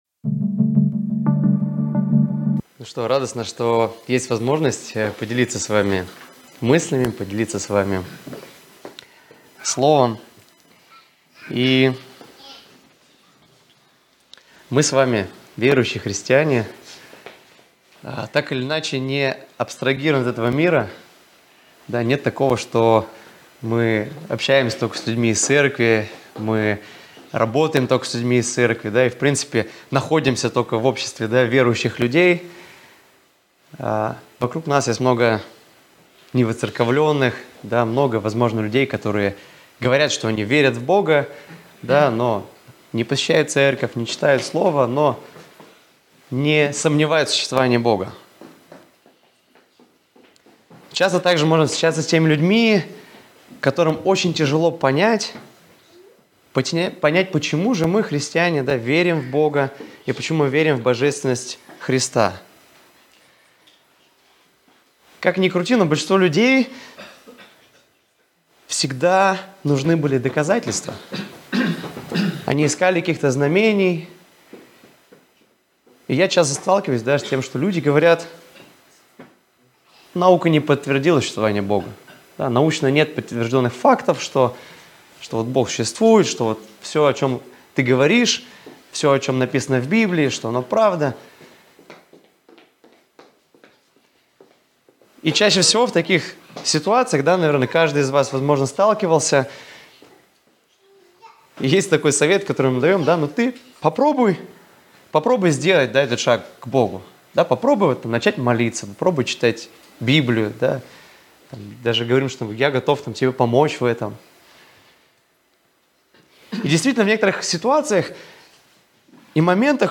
Воскресная проповедь - 2024-11-24 - Сайт церкви Преображение